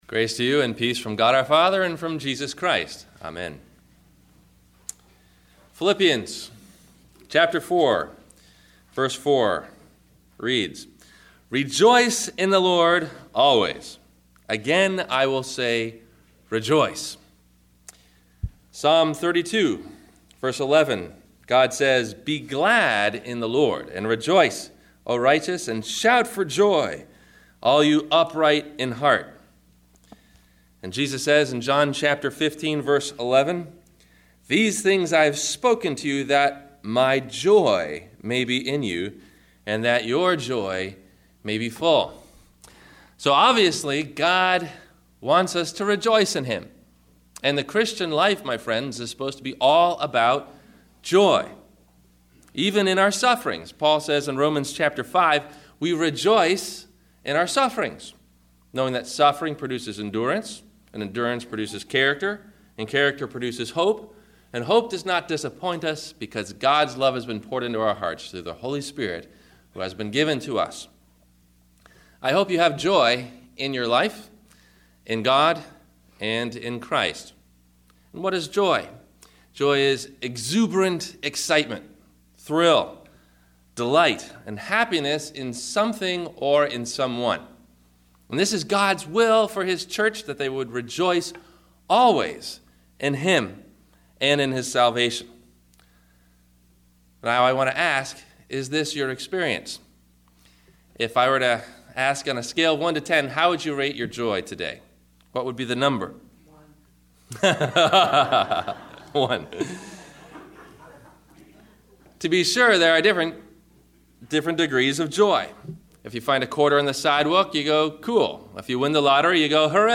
Living Under Grace – Sermon – January 16 2011